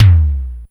DX Tom 03.wav